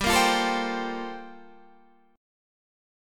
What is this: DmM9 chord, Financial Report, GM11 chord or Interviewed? GM11 chord